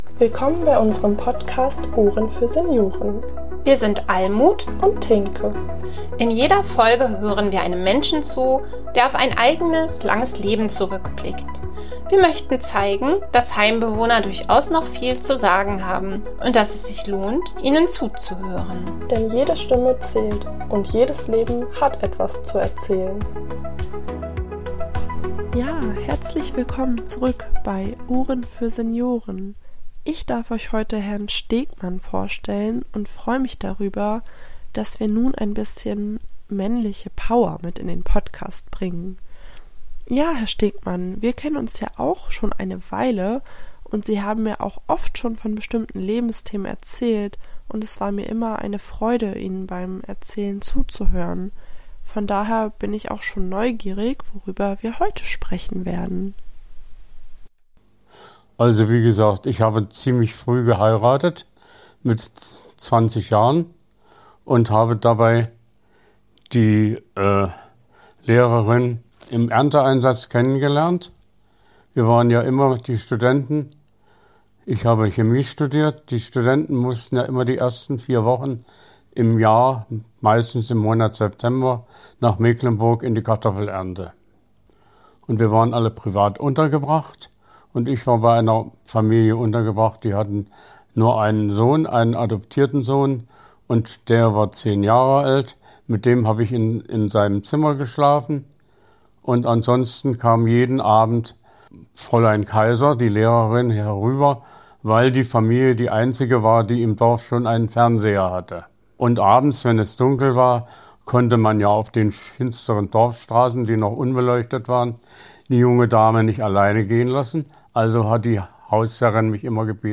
Beschreibung vor 4 Monaten In dieser Folge von Ohren für Senioren kommt zum ersten Mal ein Mann zu Wort - und was für einer! Er spricht offen über prägende Lebensthemen, verschiedene Herausforderungen und seinen spannenden Berufsweg in der Chemie.